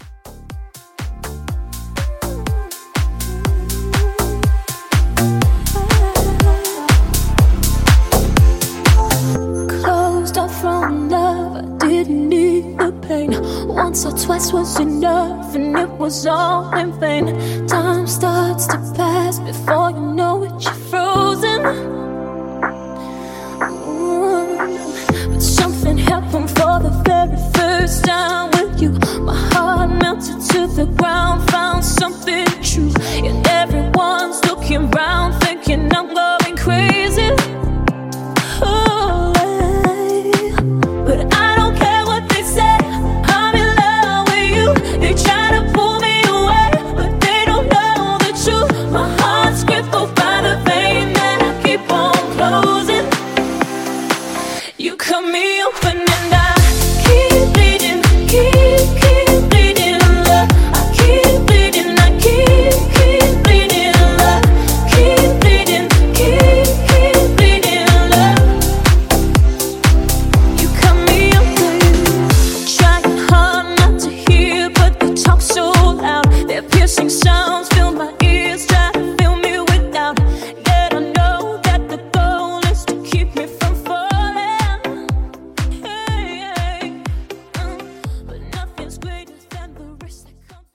Genre: 60's Version: Clean BPM: 173 Time